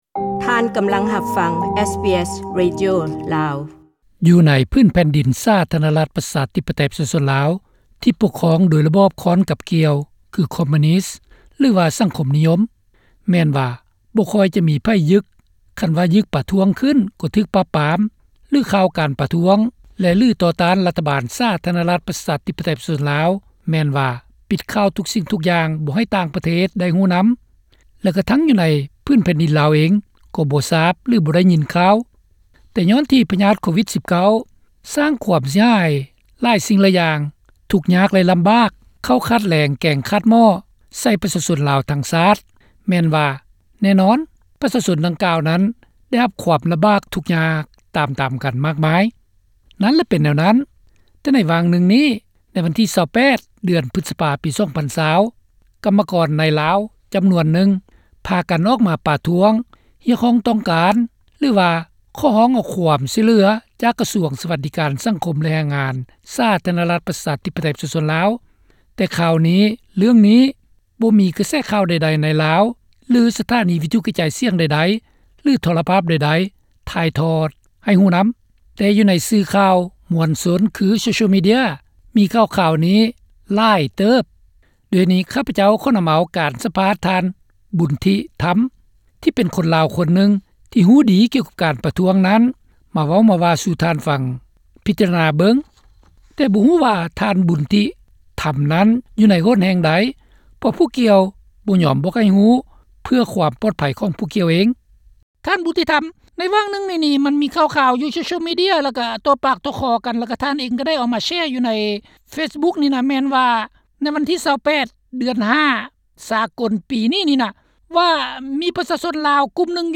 ກັມກອນໃນລາວປະທ້ວງຮຽກຮ້ອງເອົາຄວາມຊ່ວຍເຫຼືອຈາກພັກຣັຖ (ສຳພາດ)